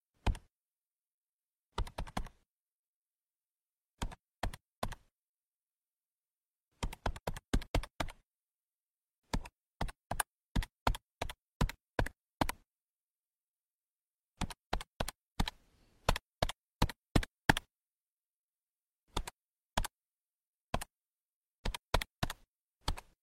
Tiếng Bấm nút máy tính toán, tính số, máy tính Casio bỏ túi…
Tiếng Bíp bíp, Tít tít… khi nhấn nút, nhập mật mã… Tiếng bíp bíp khi nhấn nút máy tính số, tính toán, máy tính Casio bỏ túi…
Thể loại: Tiếng đồ công nghệ
Description: Âm thanh bấm nút máy tính, tiếng click nhẹ của bàn phím số, tiếng nhấn phím Casio lách tách, tiếng gõ tính toán lặp lại đều đặn như nhịp điệu công việc.
tieng-bam-nut-may-tinh-toan-tinh-so-may-tinh-casio-bo-tui-www_tiengdong_com.mp3